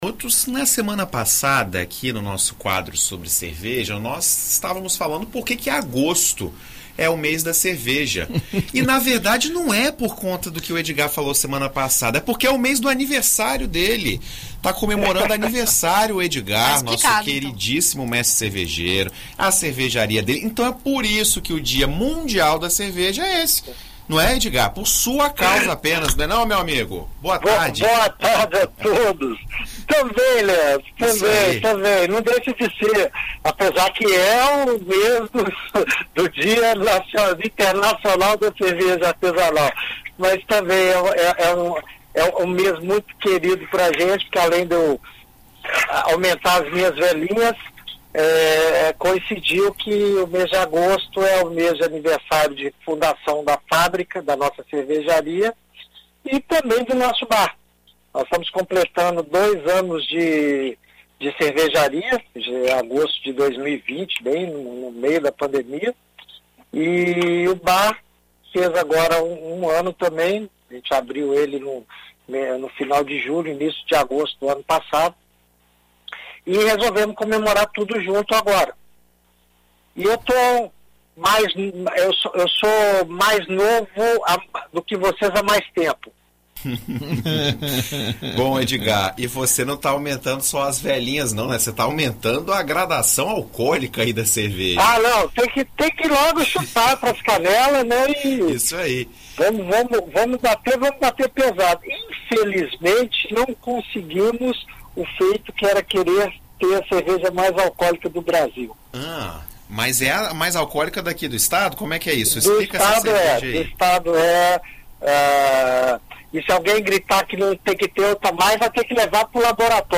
Em entrevista à BandNews FM Espírito Santo nesta sexta-feira